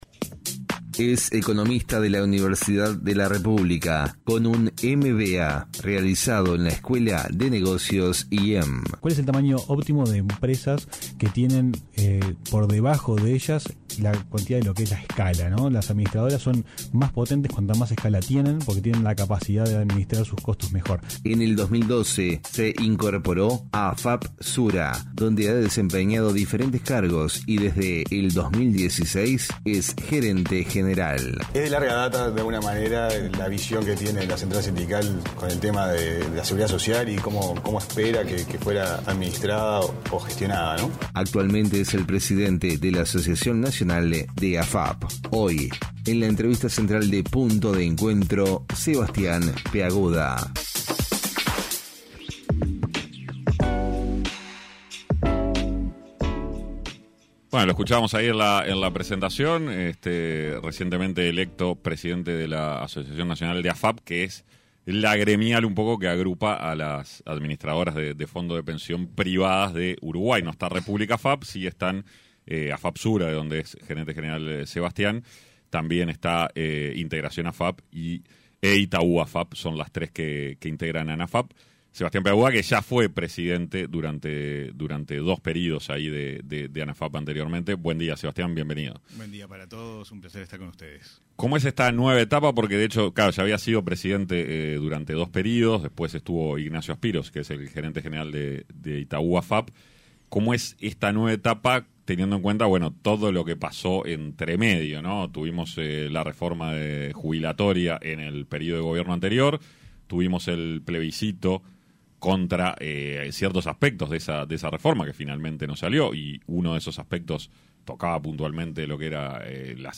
En entrevista con Punto de Encuentro